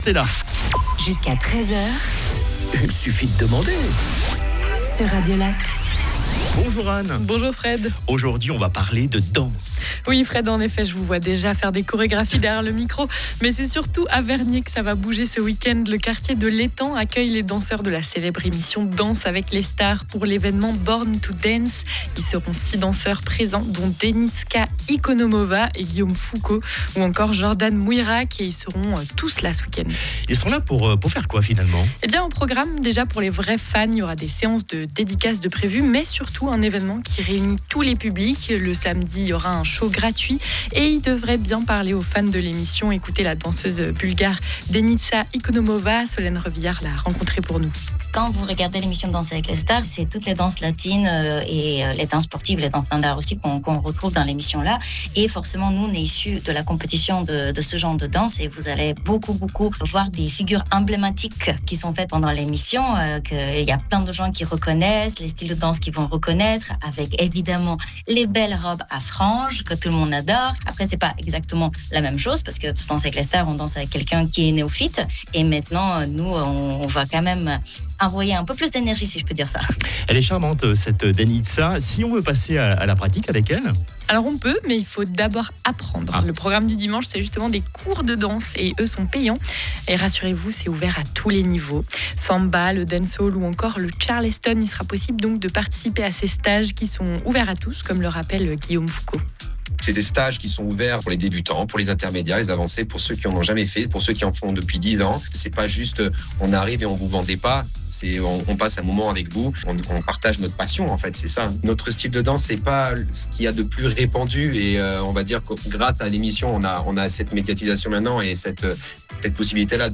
[ITW] Revue 2025: entre satire locale, danse débridée et "joyeux bordel"